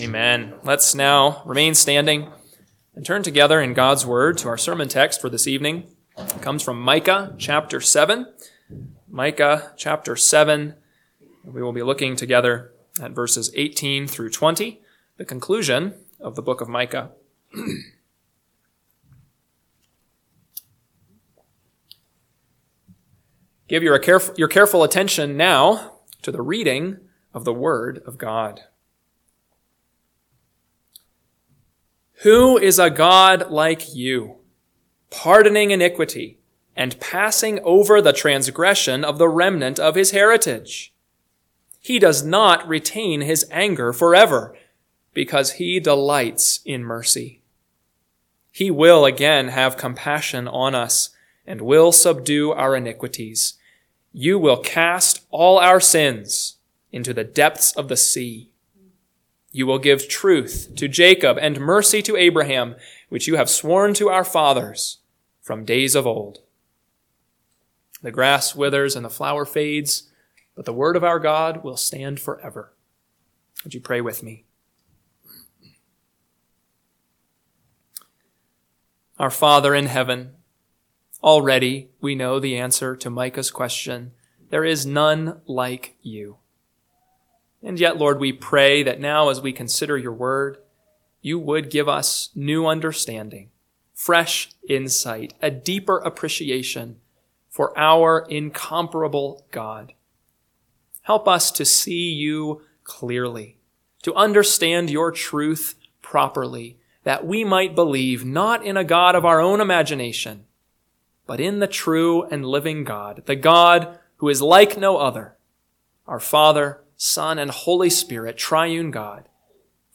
PM Sermon – 1/5/2025 – Micah 7:18-20 – Northwoods Sermons